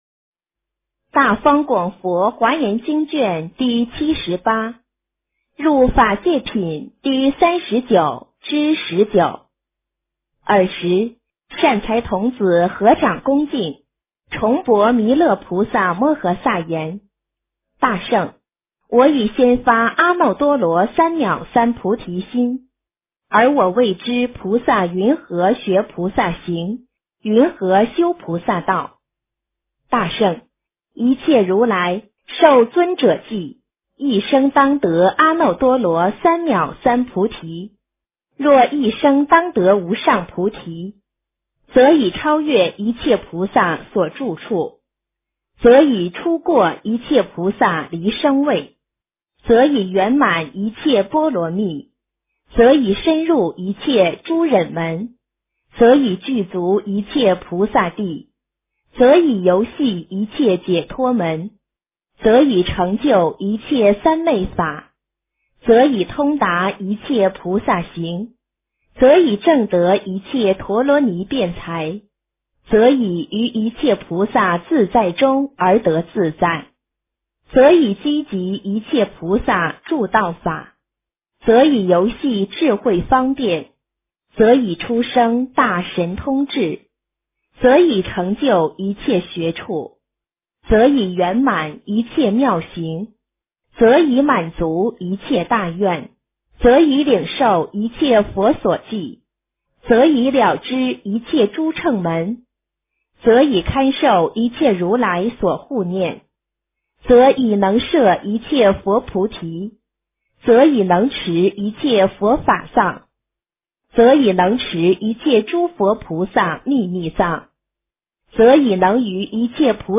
华严经78 - 诵经 - 云佛论坛